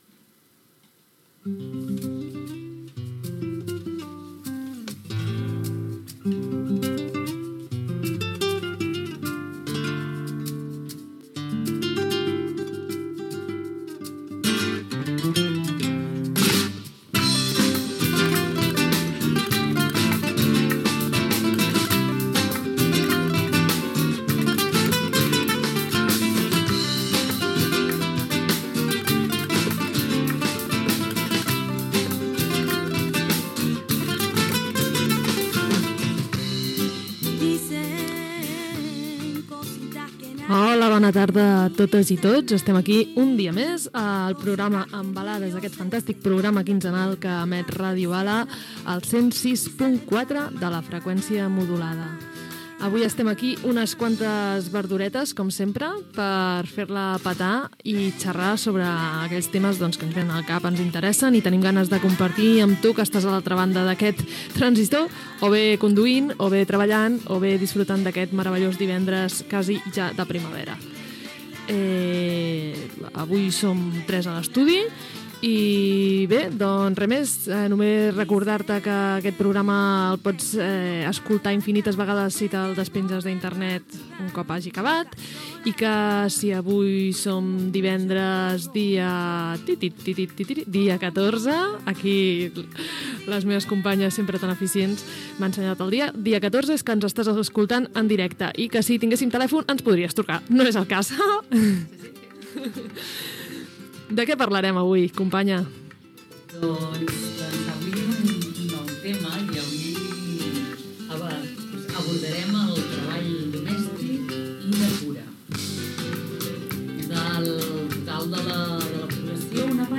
c7ece9b3f6b47d8326f23e570983d42df5762e6c.mp3 Títol Ràdio Bala Emissora Ràdio Bala Titularitat Tercer sector Tercer sector Lliure Nom programa Embalades Descripció Salutació, data i tema del programa: El treball domèstic i de cura de les dones. Gènere radiofònic Informatiu